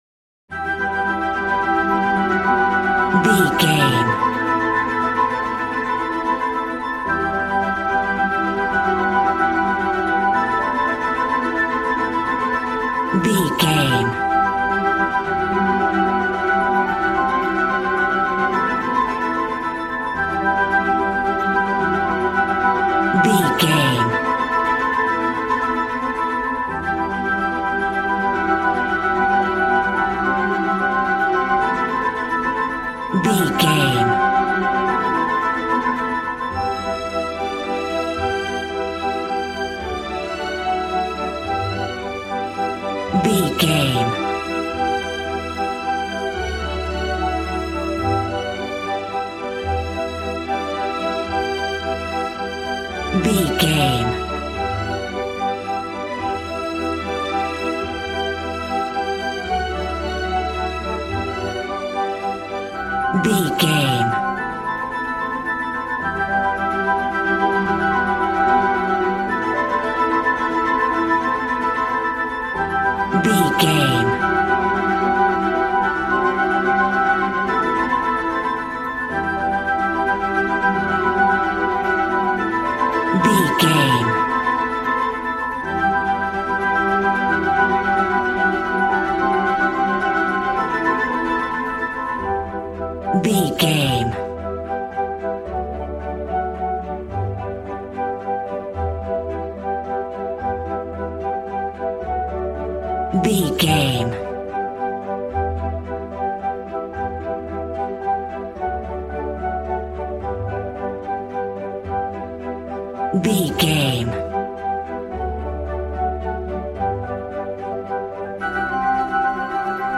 A warm and stunning piece of playful classical music.
Regal and romantic, a classy piece of classical music.
Ionian/Major
regal
piano
violin
strings